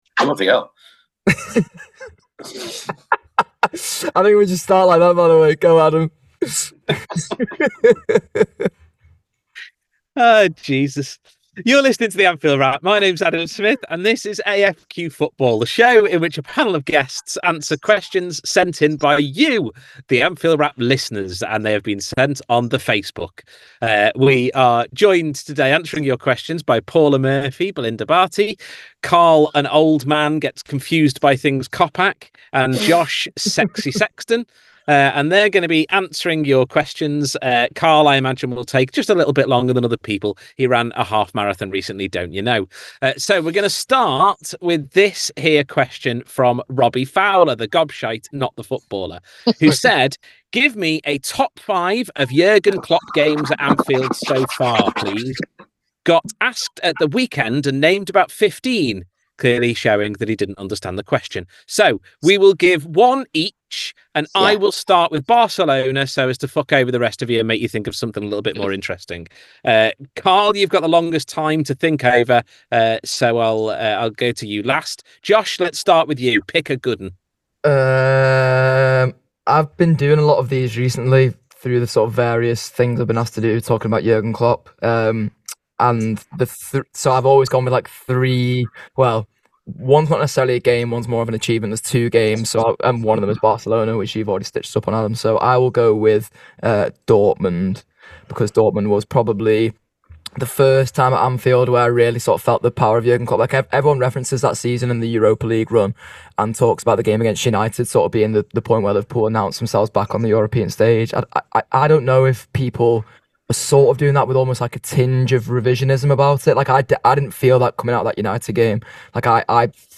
Below is a clip from the show – subscribe for more on Liverpool moments you wish you could relive…